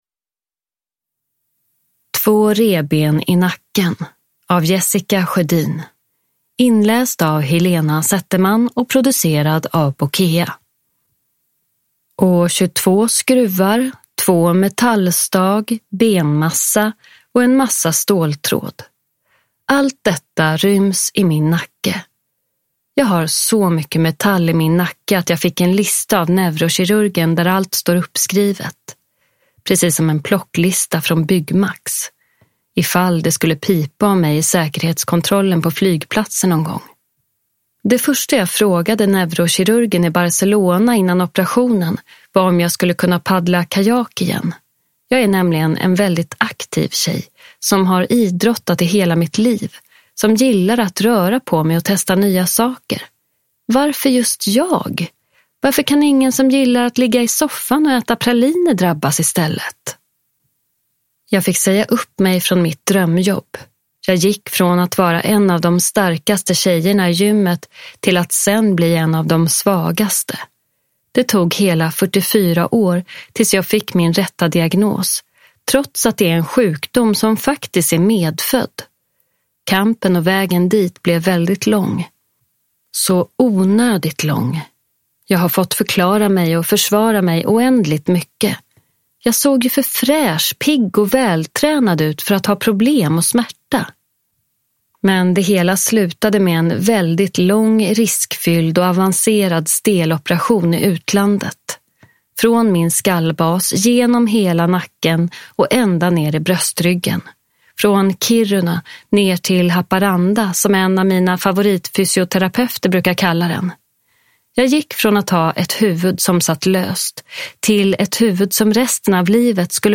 Två revben i nacken – Ljudbok
• Ljudbok